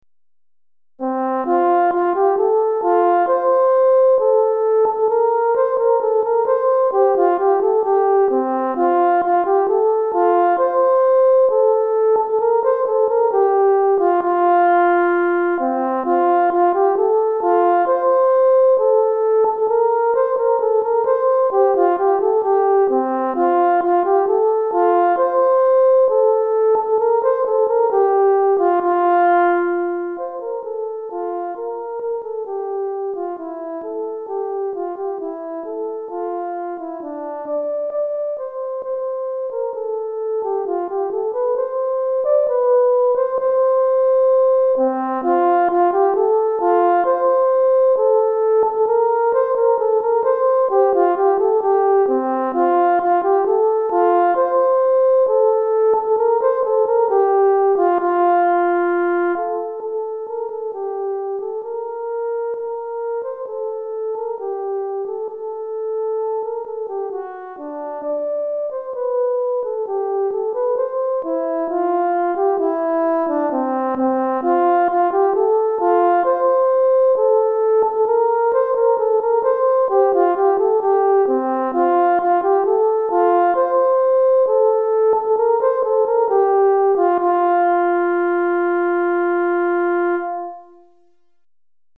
Cor en Fa Solo